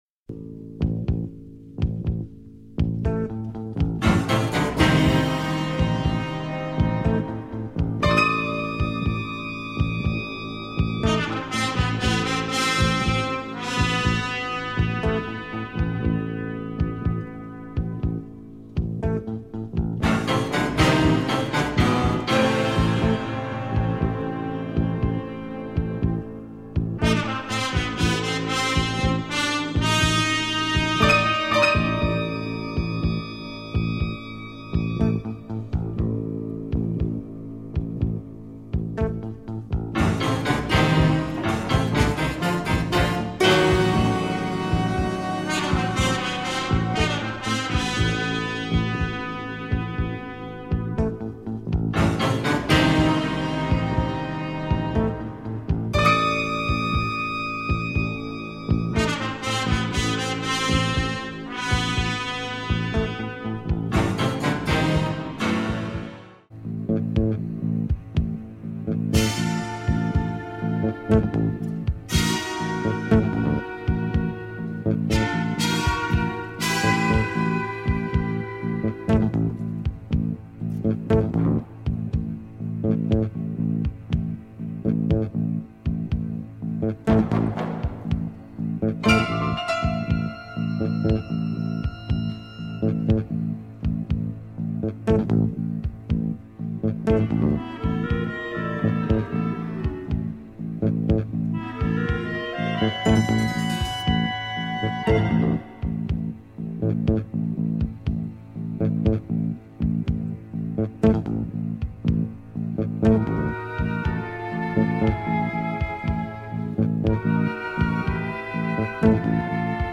soundtrack
Heavy Italian groove with breaks !